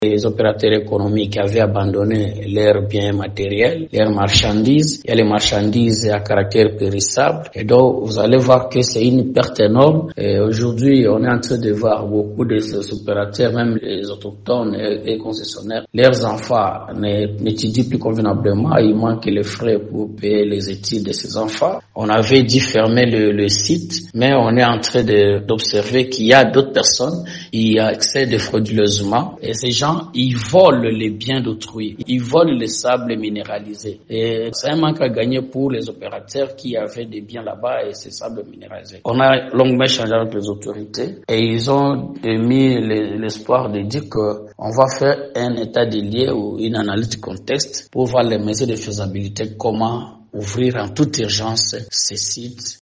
parle ici des retombées de cette réunion.